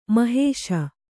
♪ mahēśa